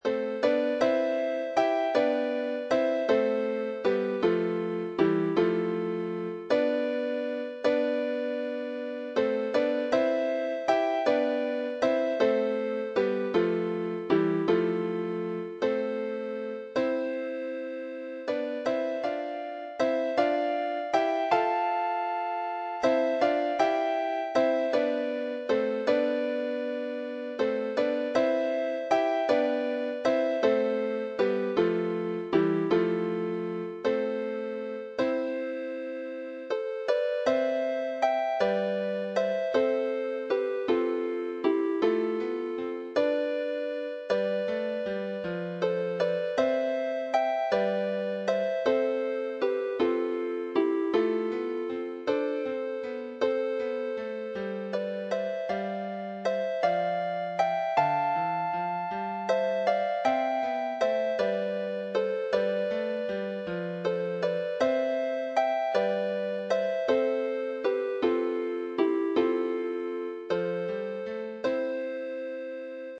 6 ~ Kelvingrove - This pleasant Scottish tune is used in the Gather book of worship as the tune for The Summons.
This version is in the less common but easier to manage meter of 3/4 instead of the traditional version with lots of dotted rhythms.
midi generated sample mp3
beginning harp sheet music - Kelvingrove - small lever harp